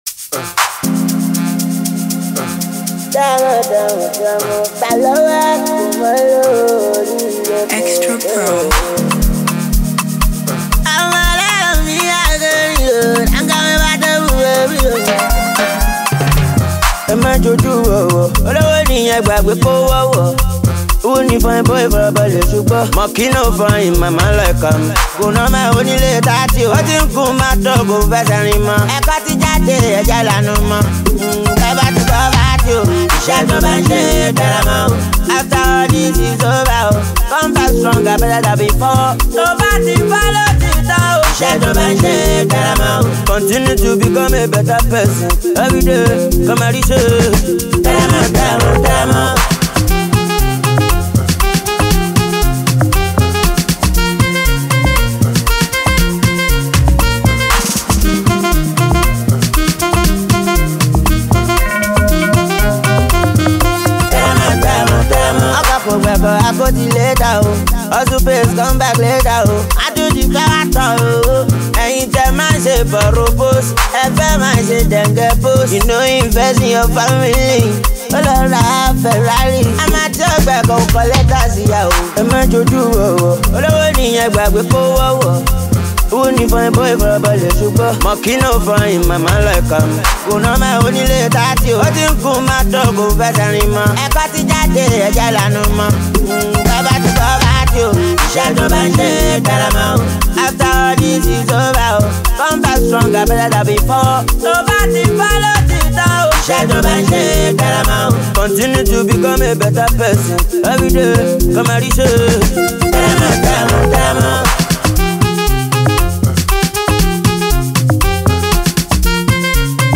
Super talented Nigerian street-hop music virtuoso
pulsating track